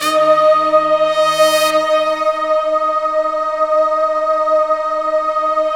SI1 BRASS0CL.wav